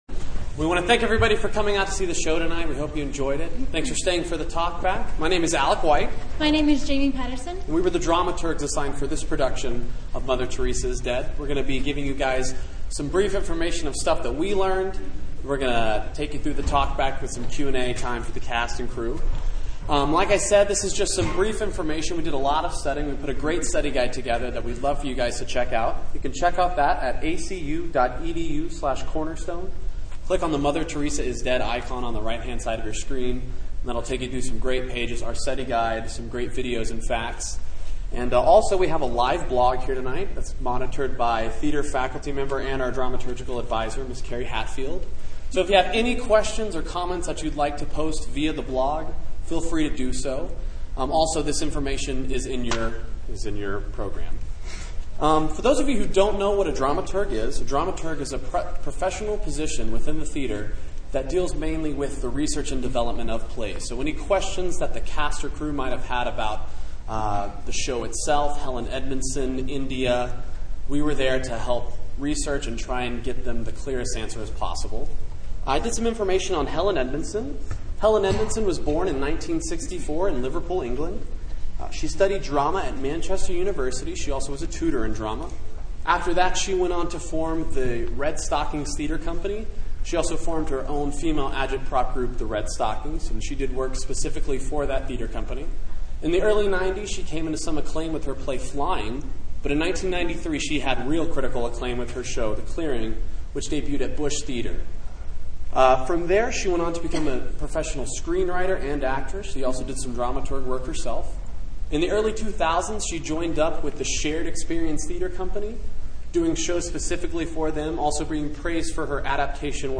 LISTEN to Mother Teresa is Dead talkback
Mother-Teresa-is-Dead-talkback.mp3